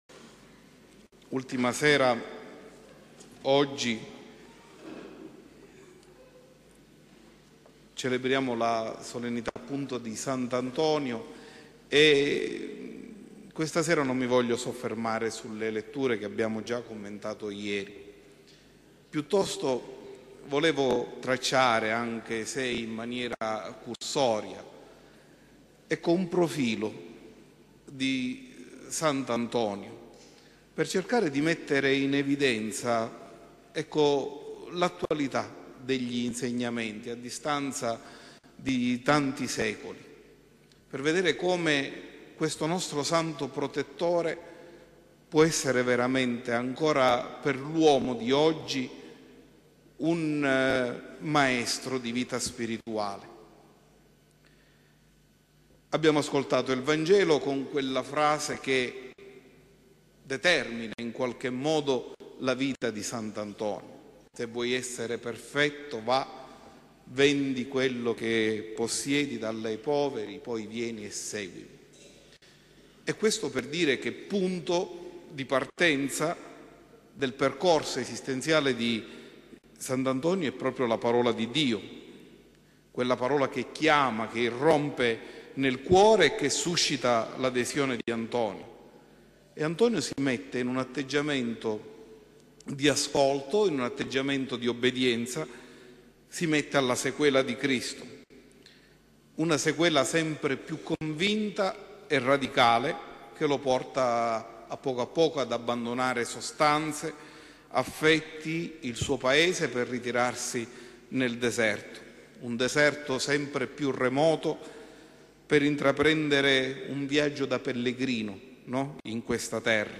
Omelia del 1� giorno di novena - 8/1/2011